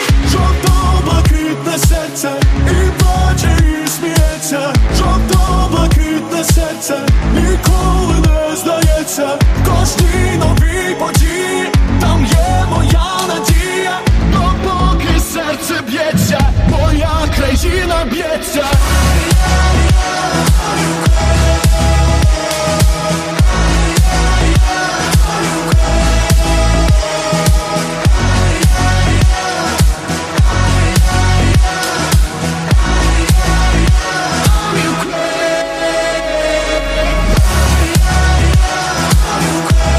поп , танцевальные